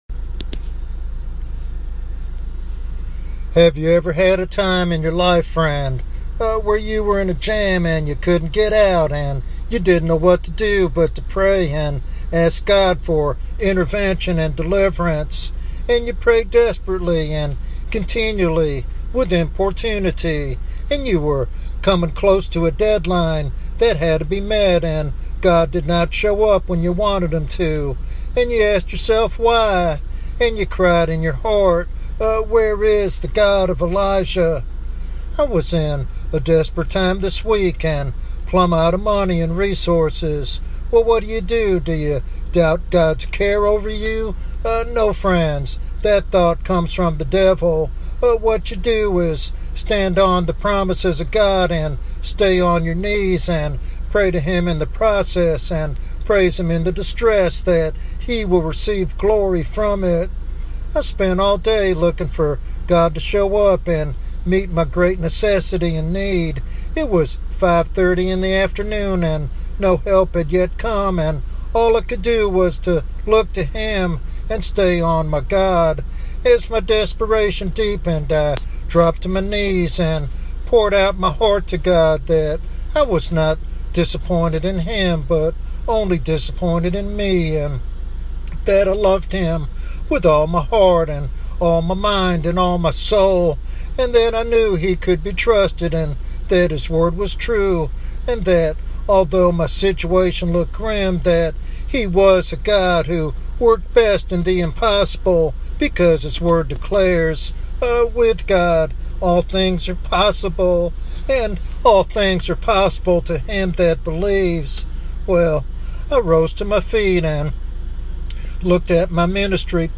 In this teaching sermon titled "Divine Delays